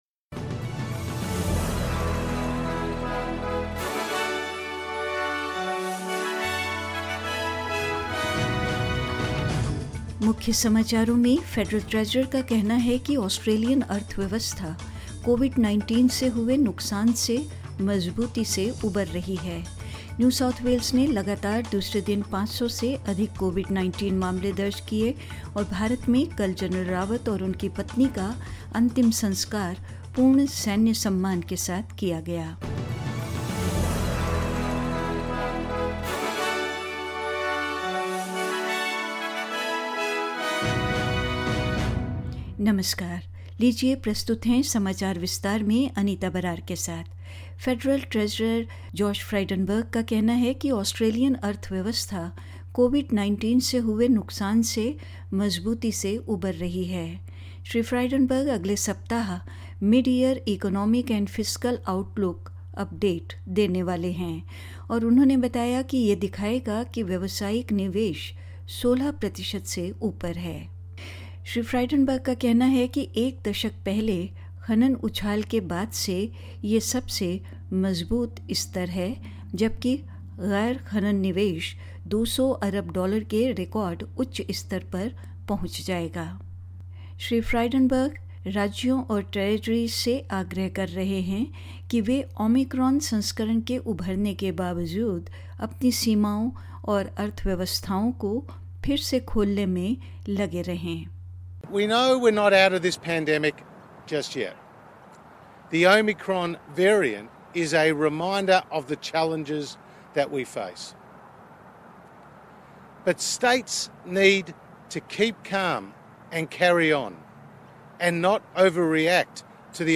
In this latest SBS Hindi news bulletin of Australia and India: The Federal Treasurer says the Australian economy is recovering strongly from the damage wrought by COVID-19; New South Wales records more than 500 Covid-19 cases for the second day in a row; In India, CDS Gen Bipin Rawat laid to rest with full military honours and more